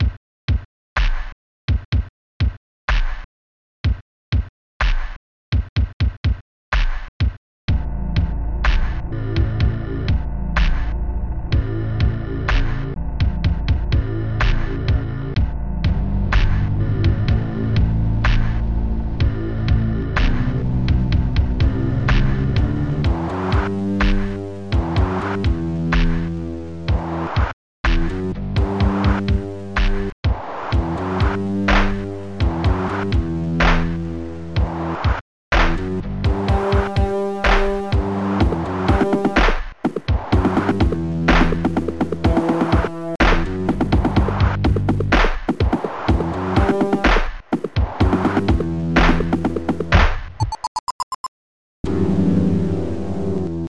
Protracker Module
Instruments BASSDRM2.SAM PBASSDRM.SAM NGHTMRE.SAM LONGBASS.SAM VACUUM.SAM CLAP1.SAM HEARTBT.SAM DIGTLBEP.SAM